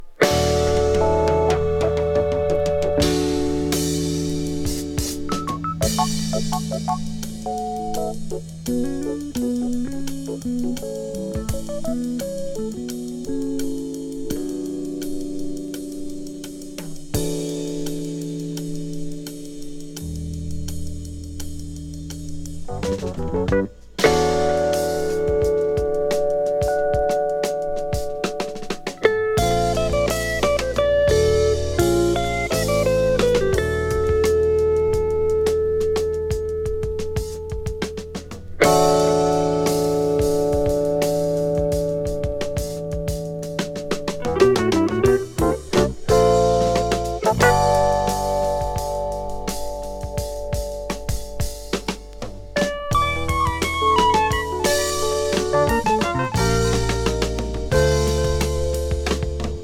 メロディアス、ジャズテイストありのカンタベリー・ジャズ・ロックの名盤。